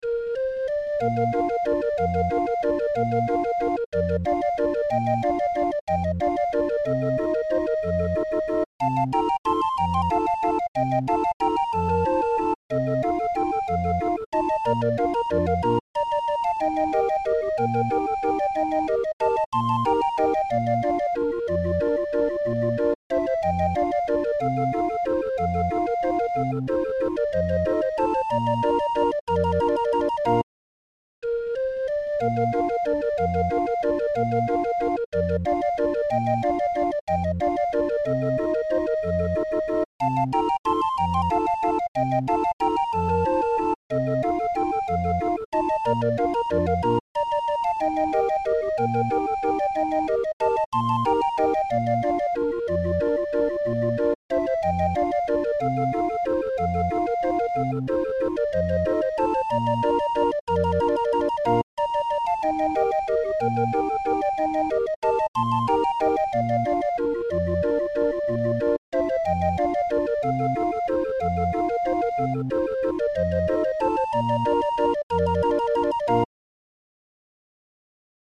Musikrolle 20-er
medley